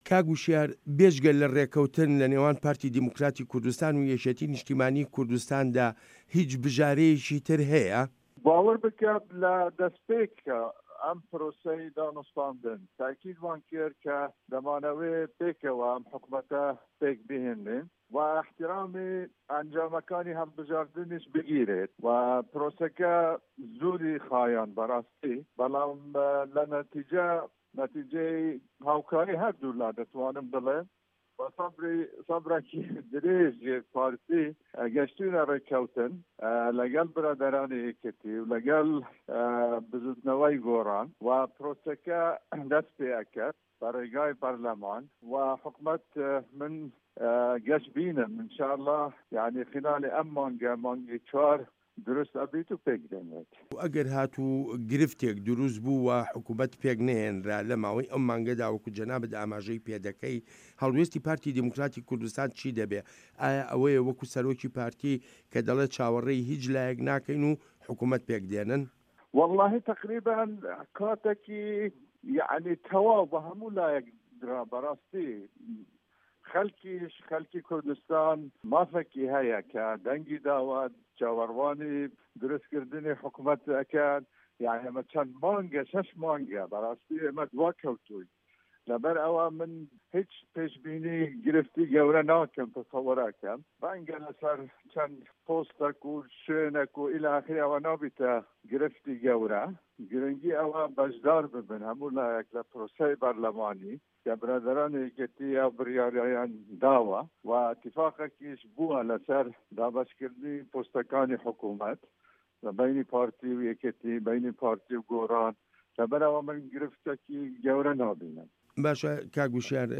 وتووێژ لەگەڵ هوشیار زێباری